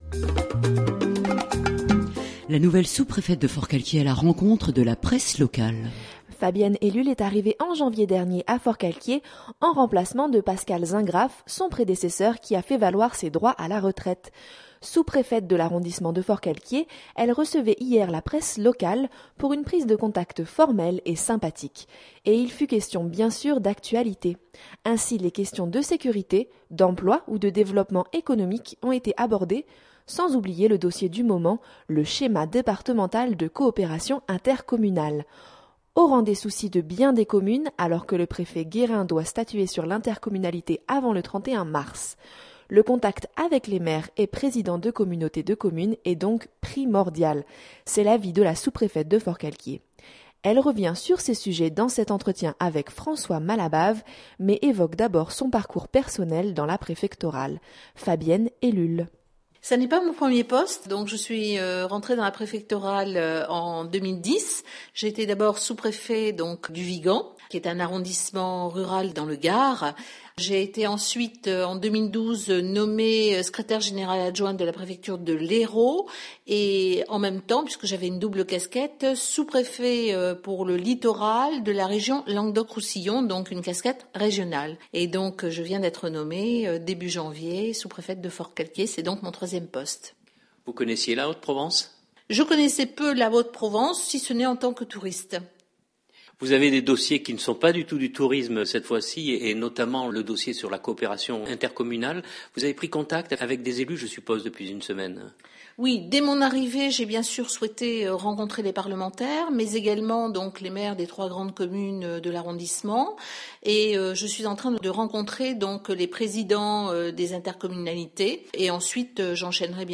C’est l’avis de la sous-préfète de Forcalquier.